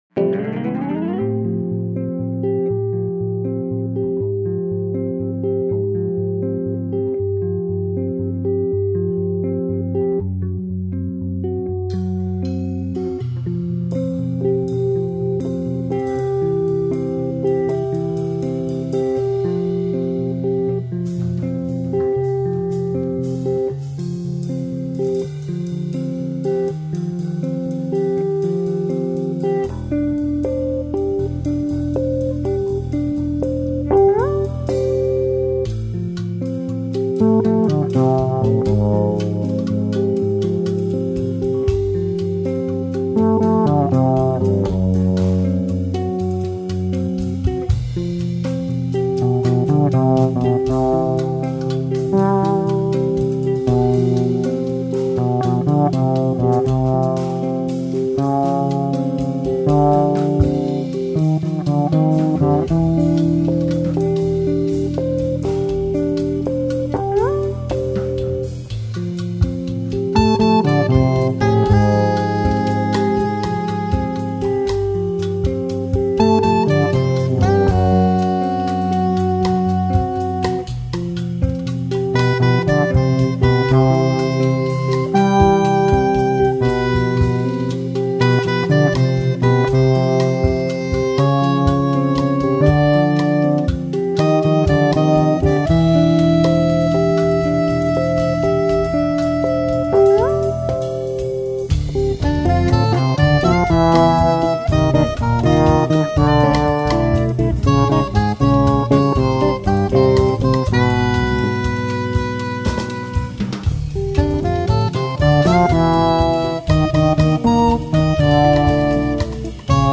2465   04:50:00   Faixa: 6    Jazz